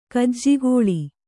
♪ kajjigōḷi